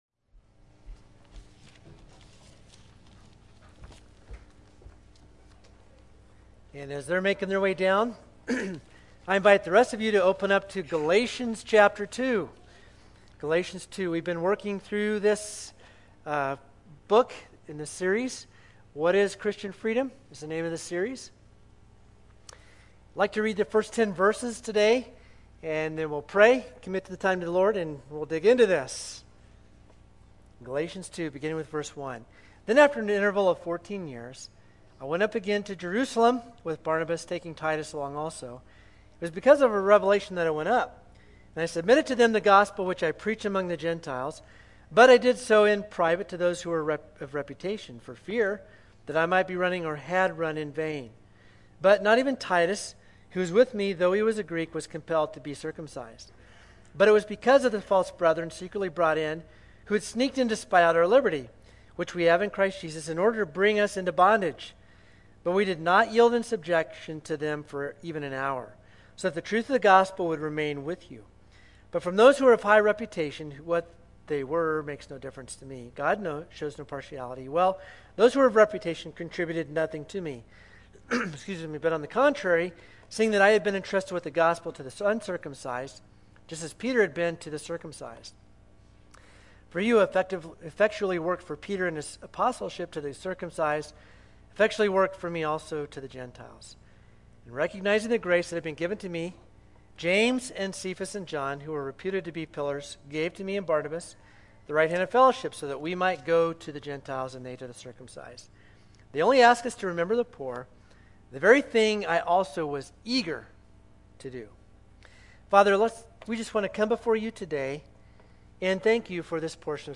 7_25-21-Sermon.mp3